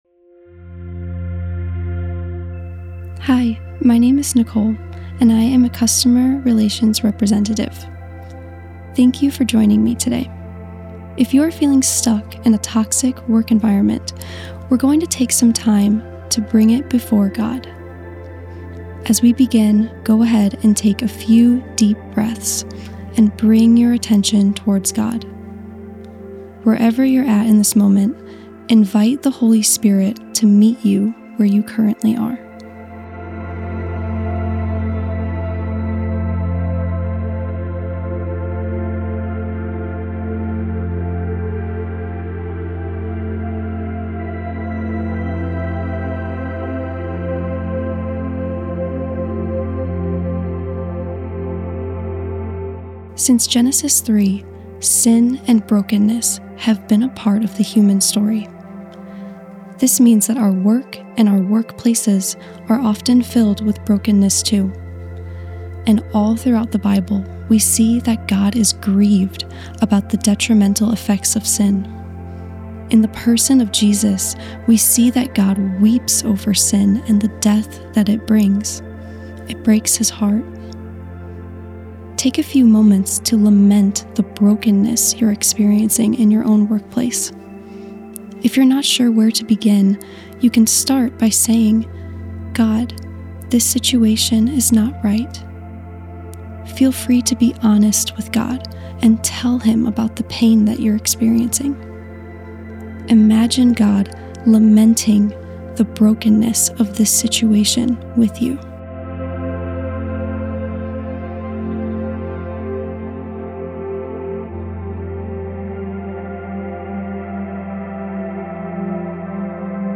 AUDIO PRAYER GUIDES